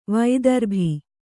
♪ vaidarbhi